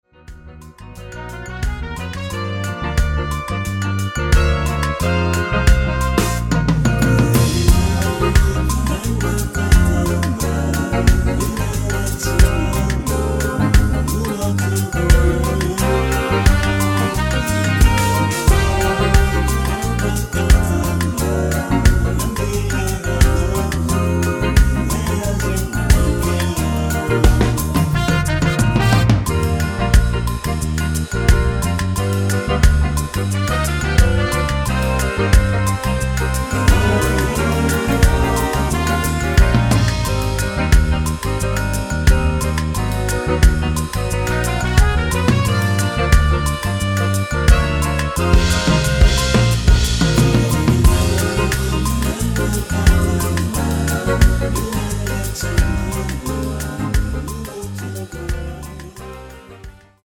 원키에서(-1)내린 코러스 포함된 MR 입니다.(미리듣기 참조)
엔딩이 길어 축가에 사용 하시기 좋게 엔딩을 짧게 편곡 하였습니다.(원키 코러스 버전 미리듣기 참조)
Eb
앞부분30초, 뒷부분30초씩 편집해서 올려 드리고 있습니다.